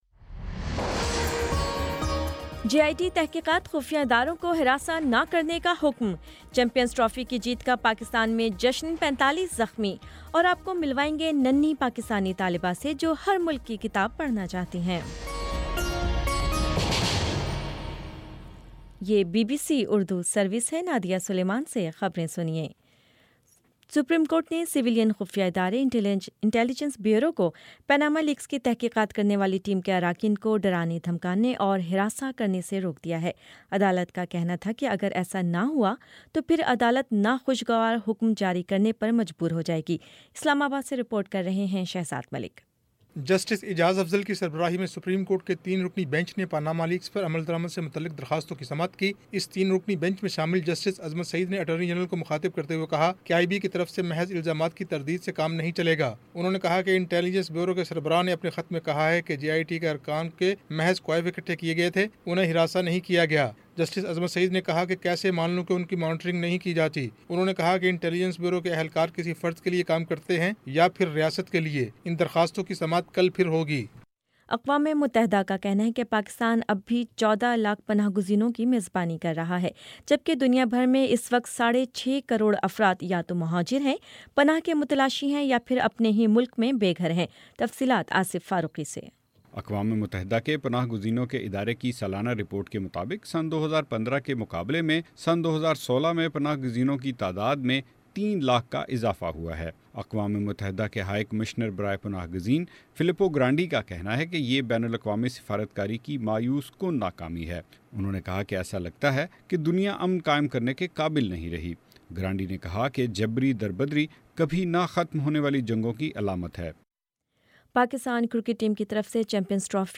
جون 19 : شام پانچ بجے کا نیوز بُلیٹن